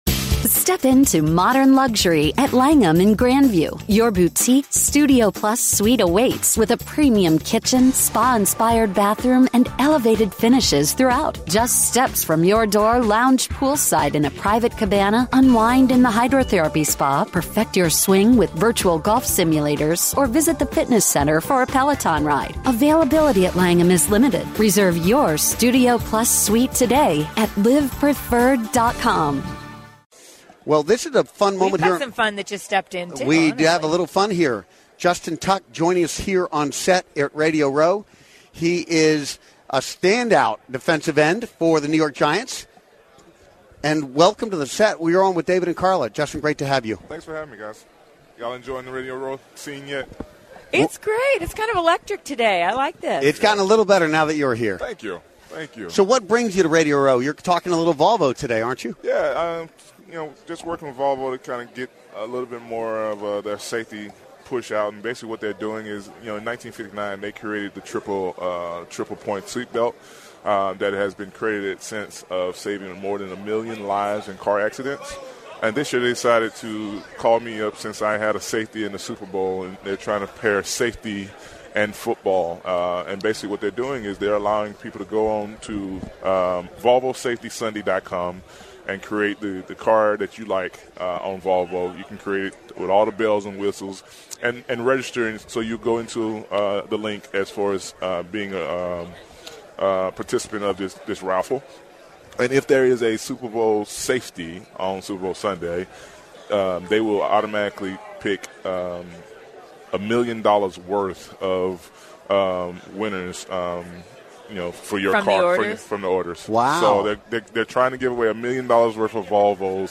Former Giants DE Justin Tuck joins The Boss & The Gloss live on Radio Row